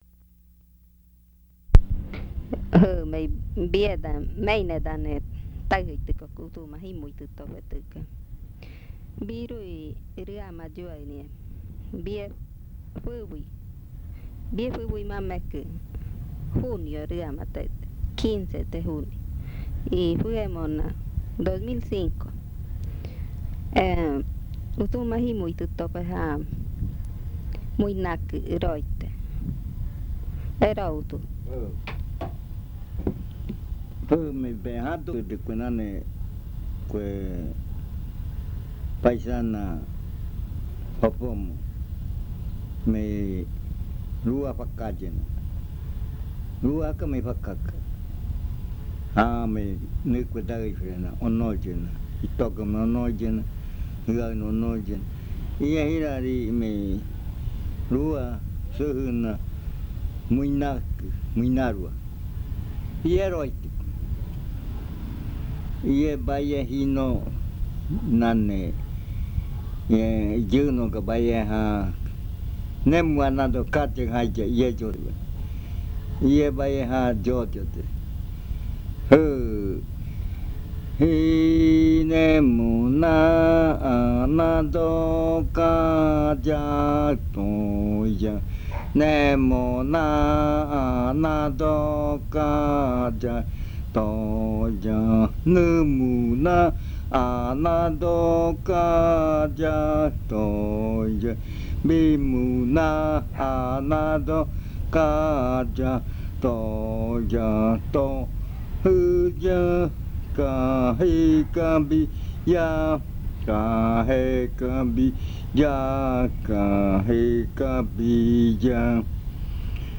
Leticia, Amazonas
This chant is part of the collection of chants from the Yuakɨ Murui-Muina (fruit ritual) of the Murui people